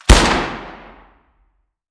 weap_juliet_launch_atmo_int_04.ogg